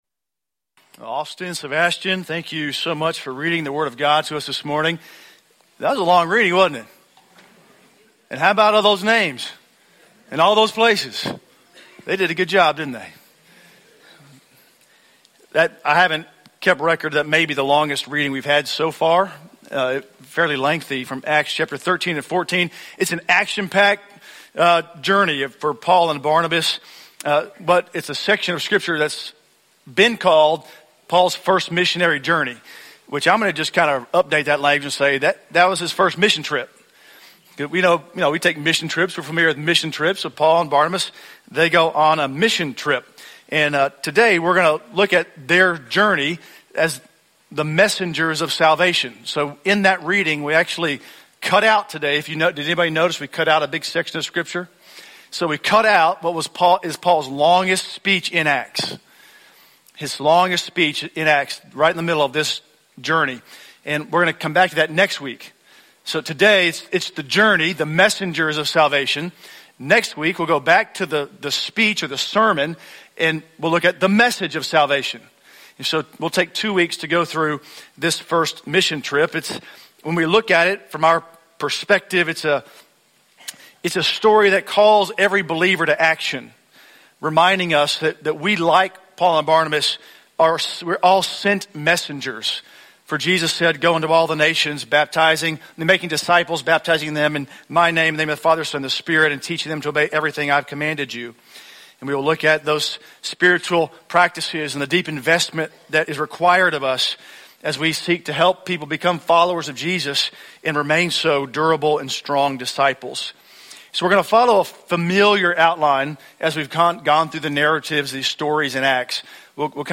The Spirit Acts: The Messengers of Salvation - English Sermons - 10:15